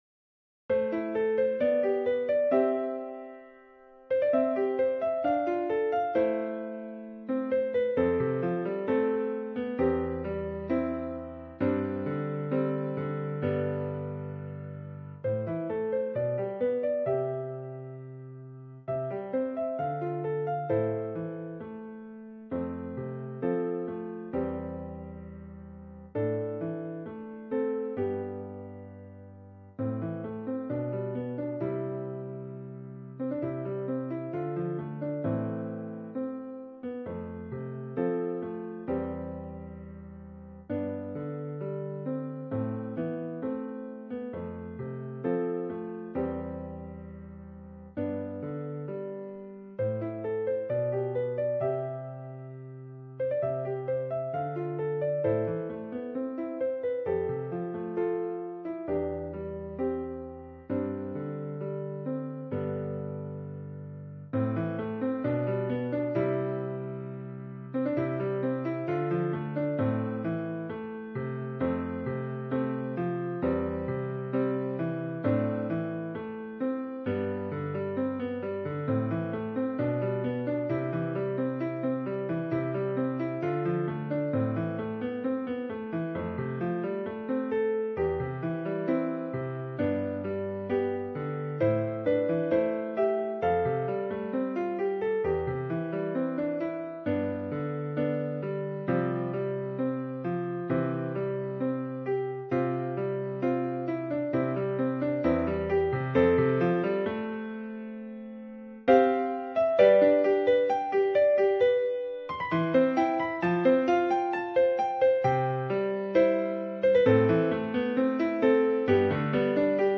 minus piano only